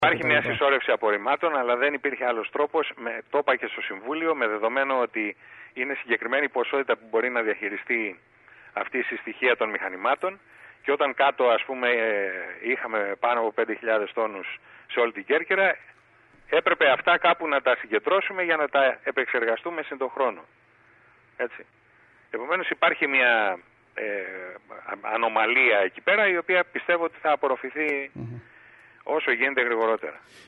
Κέρκυρα: Δηλώσεις δημάρχου για Λευκίμμη και Τεμπλόνι (audio)
Στις εξελίξεις στο θέμα των απορριμμάτων αναφέρθηκε μιλώντας στην ΕΡΤ Κέρκυρας ο δήμαρχος Κώστας Νικολούζος, ο οποίος επεσήμανε ότι προχωράει κανονικά το έργο μεταφοράς σε πρώτη φάση των 8000 τόνων απορριμμάτων στο ΧΥΤΑ Λευκίμμης ενώ παράλληλα βρίσκεται σε εξέλιξη η επισκευή των εγκαταστάσεων του ΧΥΤΑ και η σύνδεση του με τα δίκτυα της ΔΕΗ και της ΔΕΥΑΚ, έτσι ώστε να δοθεί και η άδεια για ΧΥΤΥ. Ο δήμαρχος είπε ακόμα ότι έως το τέλος Ιουλίου θα  ξεκινήσει η  διαδικασία για την εξεύρεση  του εργολάβου που θα αναλάβει το εργοστάσιο ολοκληρωμένης διαχείρισης καθώς η χρηματοδότηση είναι εξασφαλισμένη.